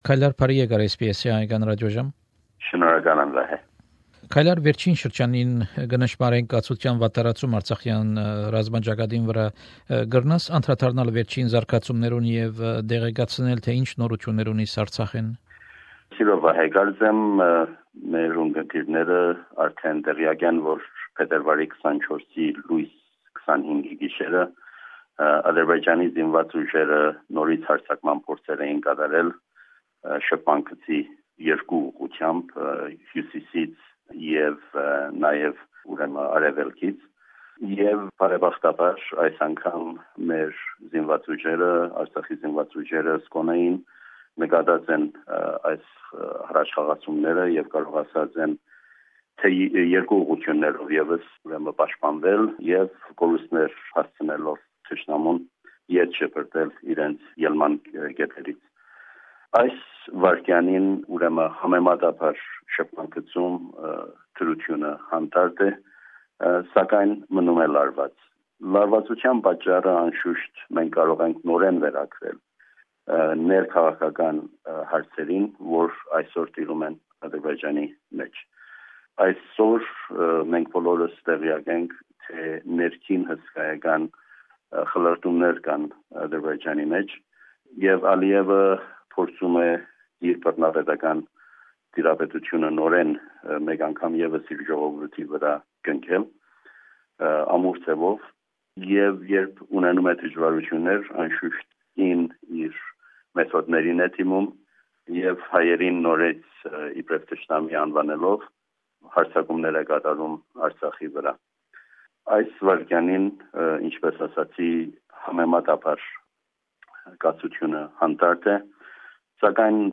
An interview with Kaylar Michaelian, Permanent Representative of the Republic of Artsakh to Australia. Topics included the recent outbreak of violence in Artsakh, constitutional referendum and toys to children of Artsakh.